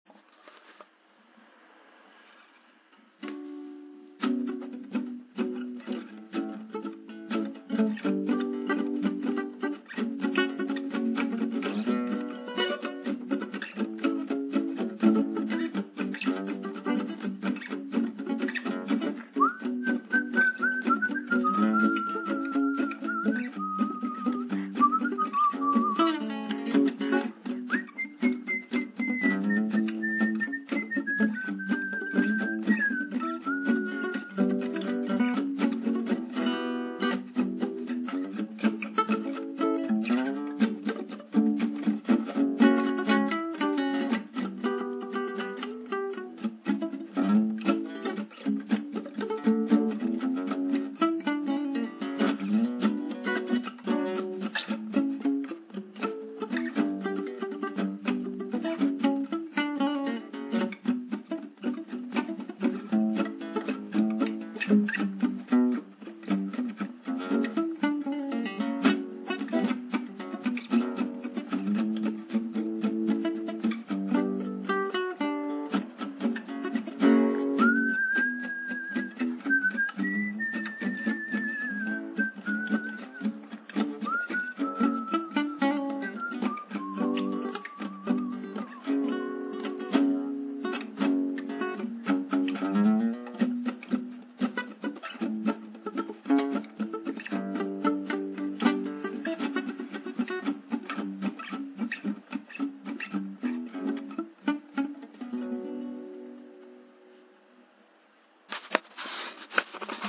Voici par exemple, un lien avec le fichier BmAGF#.mp3, qui contient un anatole en Si mineur :
Anatole Bm A G F# à la guitare